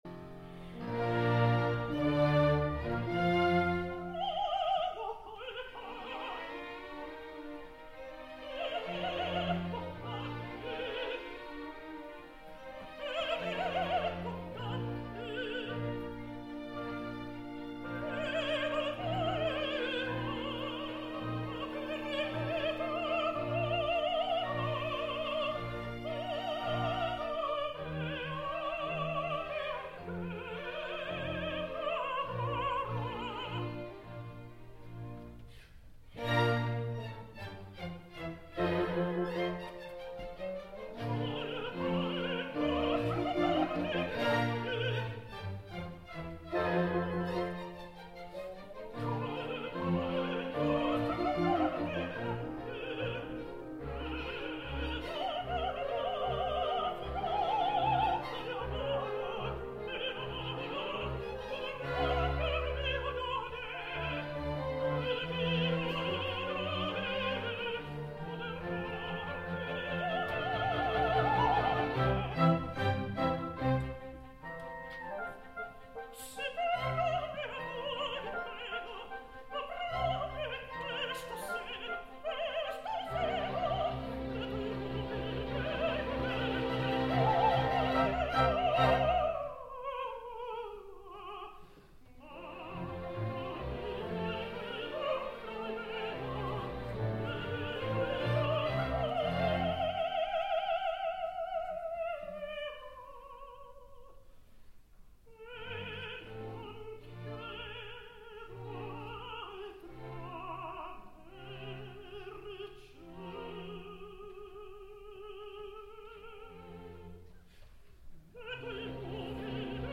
Del primer acte escoltem a Fagioli cantant “Non ho colpa”.
Conductor Marc Minkowski
ROH Covent Garden de Londres, 15 de novembre de 2014